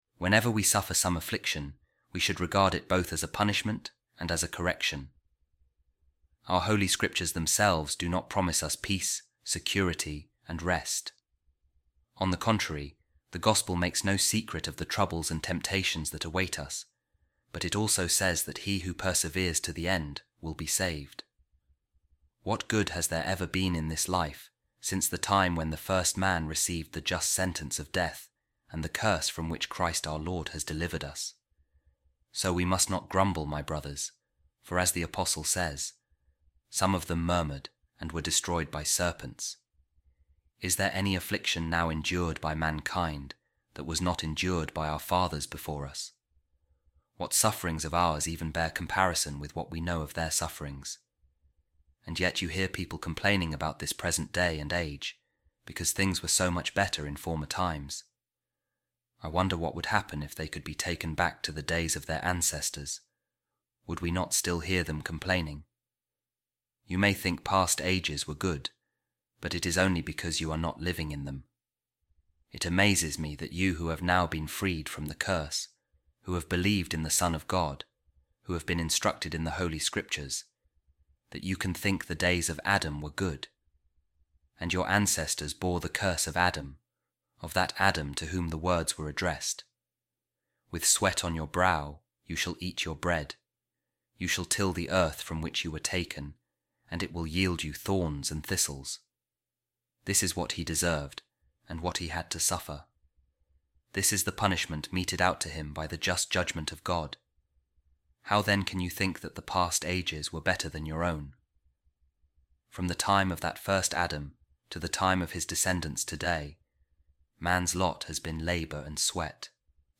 A Reading From The Sermons Of Saint Augustine | He Who Perseveres To The End Will Be Saved